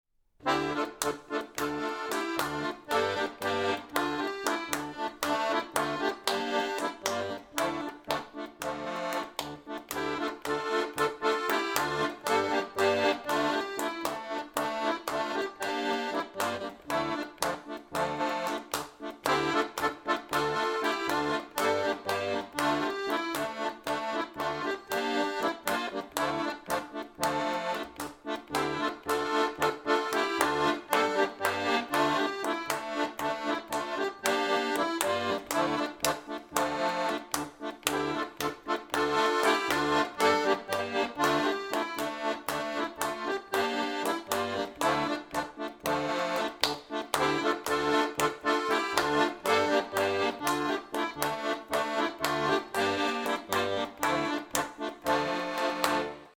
DERE GELIYOR - Playback im langsamen Übetempo
deregeliyor_akk_kla.mp3